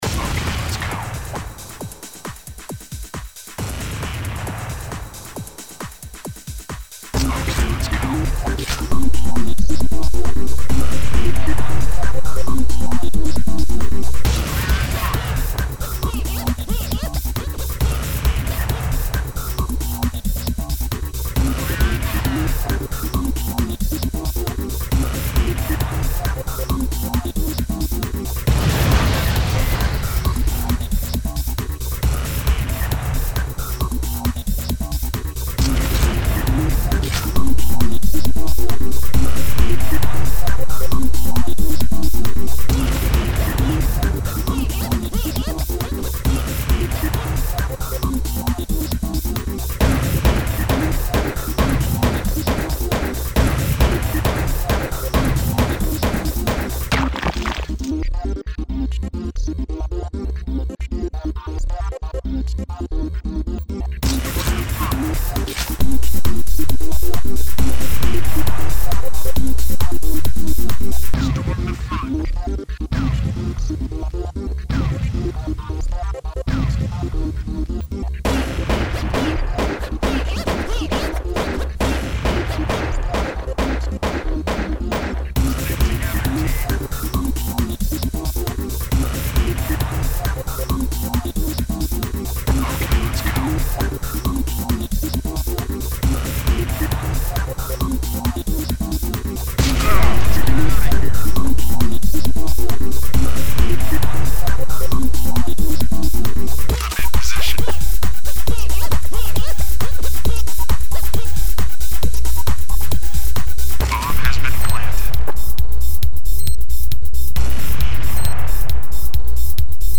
Electronica
Shaman Longtrain Off the Ice These two I made with Acid and a bit o' guitar: de_techno (For Counter-Strike fans) anger Rock Here's a mistake-filled demo of one of my originals.